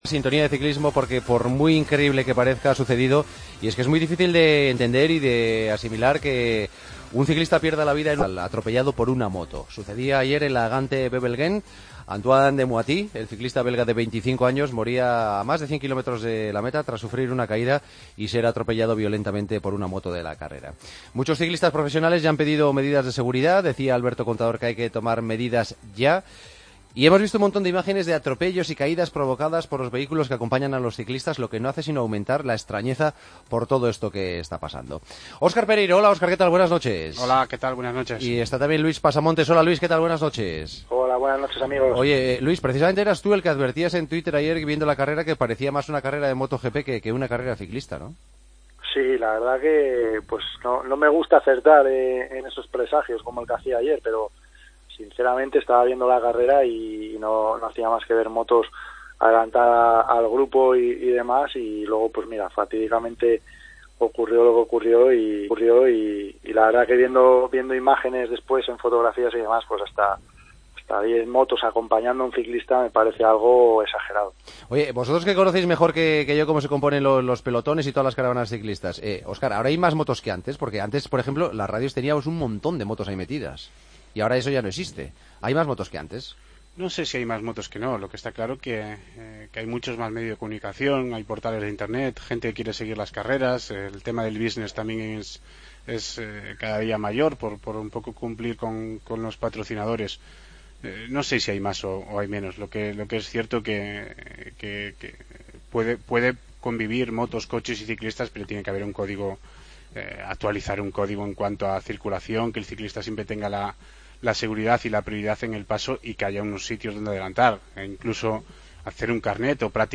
AUDIO: Hablamos con los dos ex ciclistas, y comentaristas de la Cadena Cope, sobre el trágico suceso que acabó con la vida del ciclista...